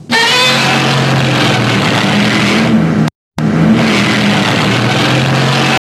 File:Godzilla Kaijuverse Roar.wav
Godzilla_Kaijuverse_Roar.wav